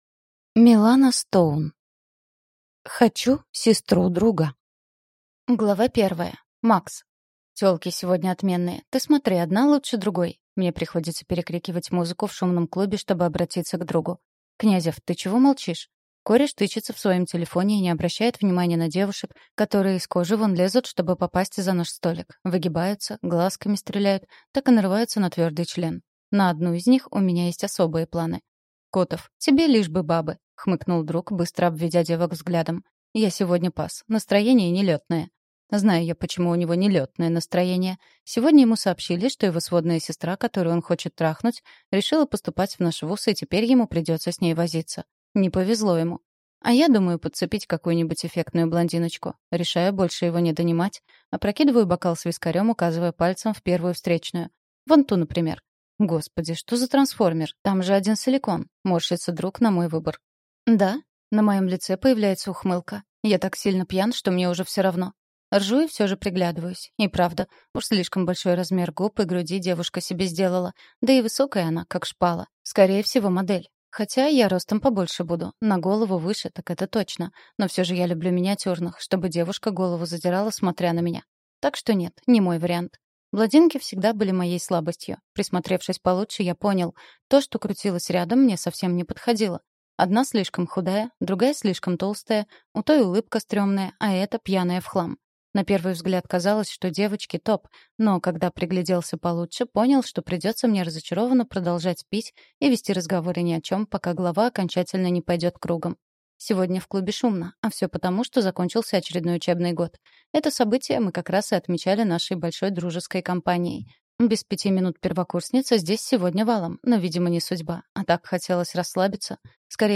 Аудиокнига Хочу сестру друга | Библиотека аудиокниг